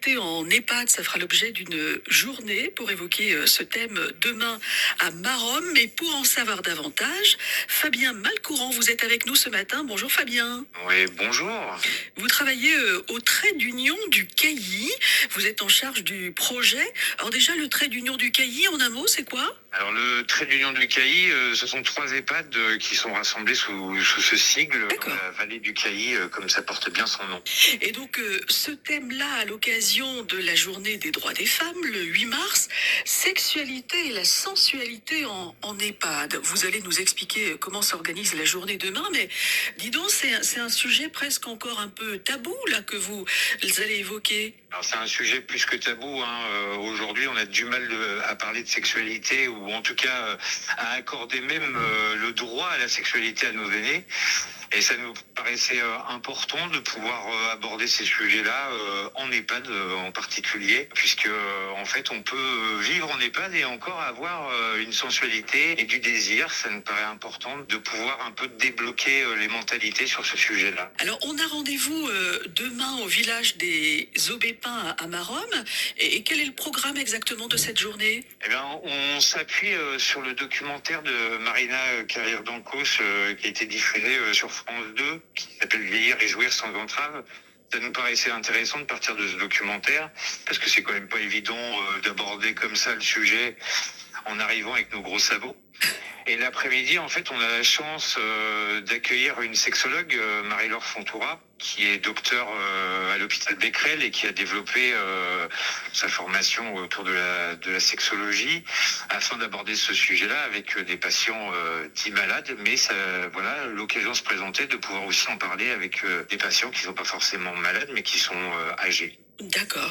Interview – Journée sensualité et sexualité en EHPAD